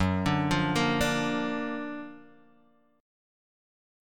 F#+M7 chord